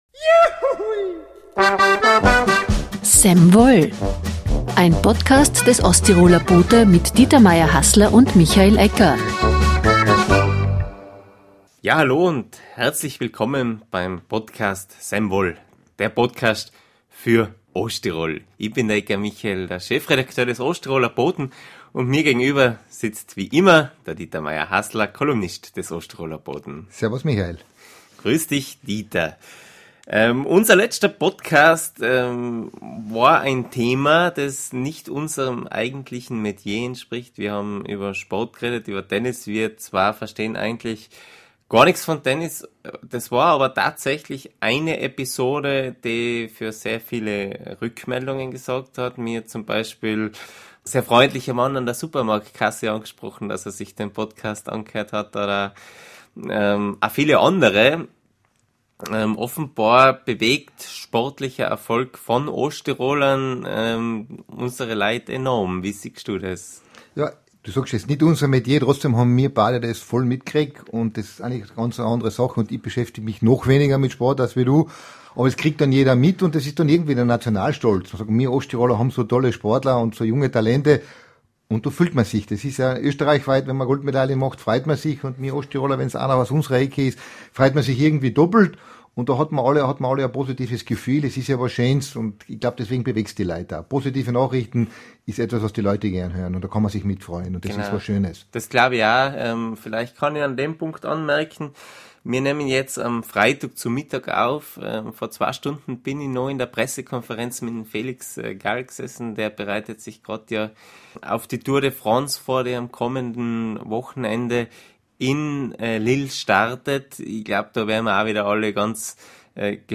Warum gibt es gerade in Kartitsch so viele Bergfeuer? Und welche Bedeutung hat das Fest für die Junge? Ein Gespräch über Glauben, Krieg – und Hoffnung.